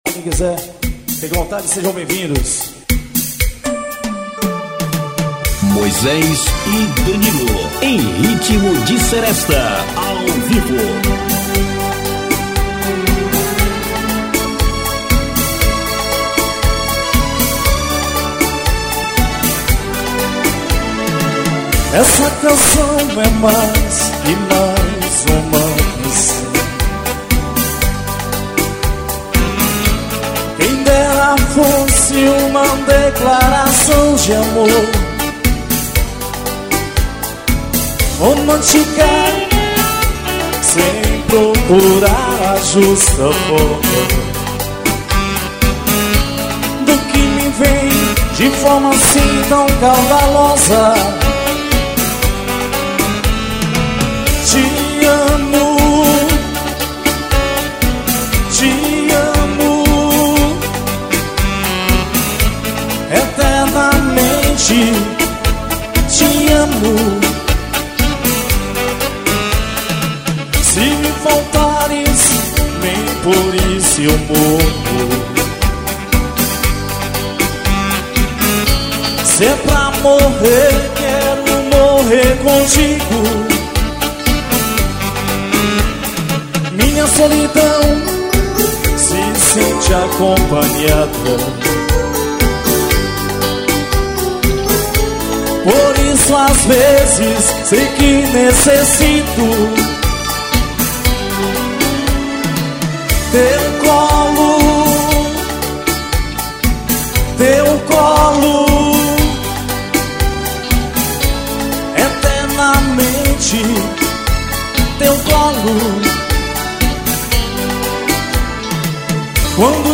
SERESTA.